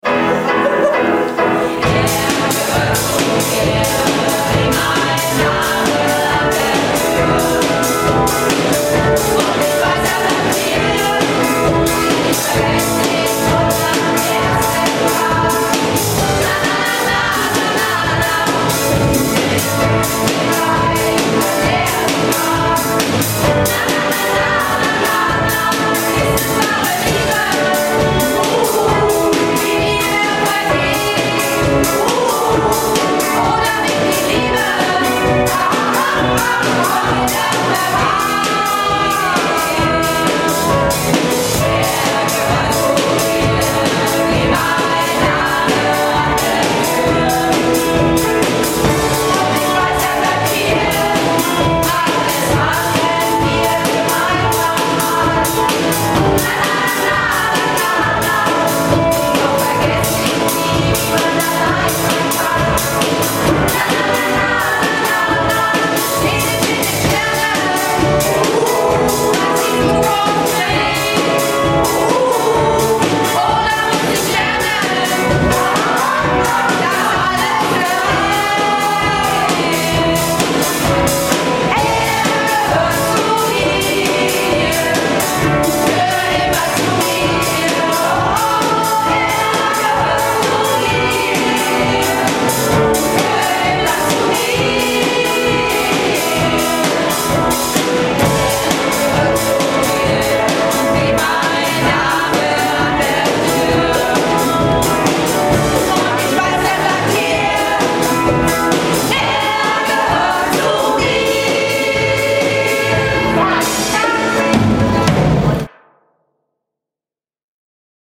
Malle Diven - Probe am 14.09.11